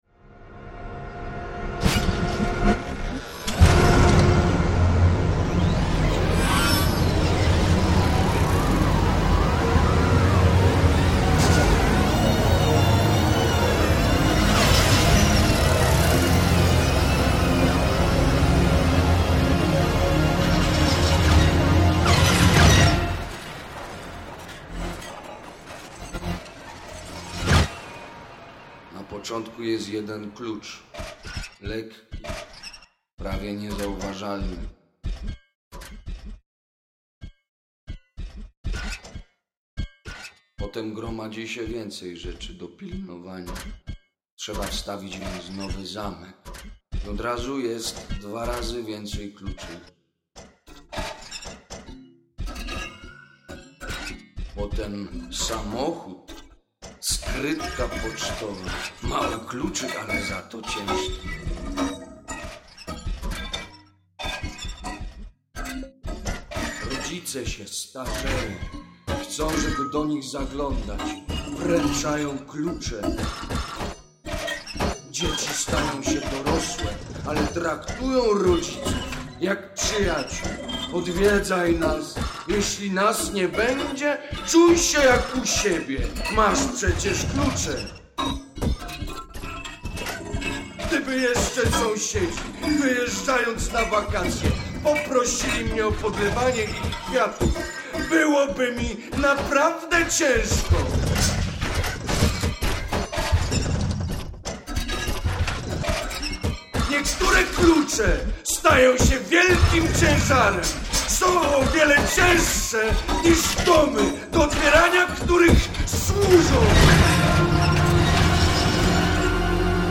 Audycja na lektora i elektronikę. 2001